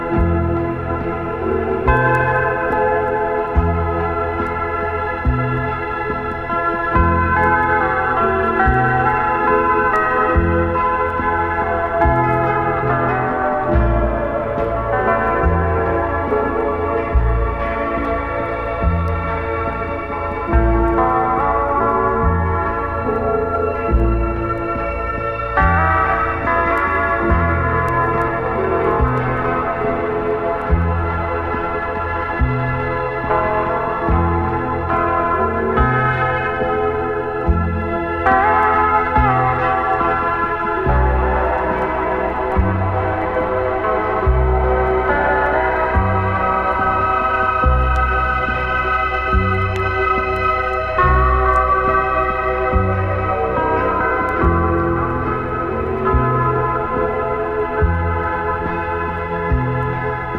月面漂着アンビエント・ミュージック！アウタースペース・スチールギター・イージーリスニング